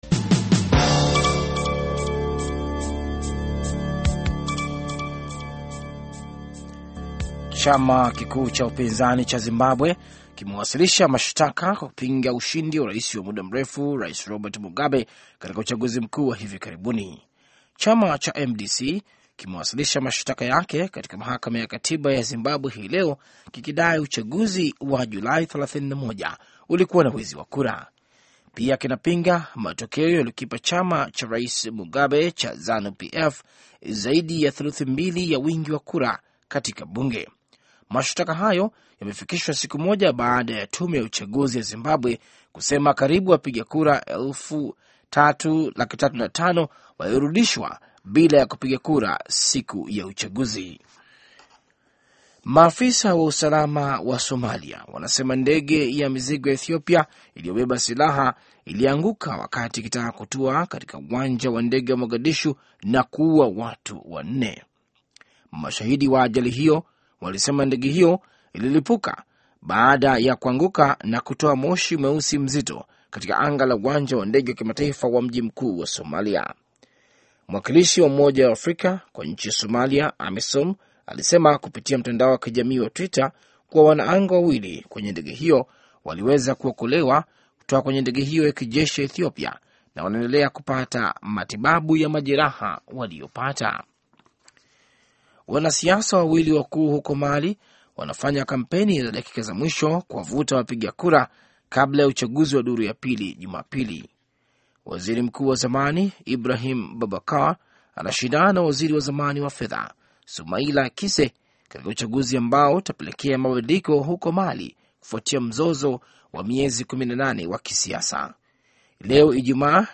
Taarifa ya Habari VOA Swahili - 5:25